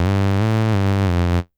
Bass_03.wav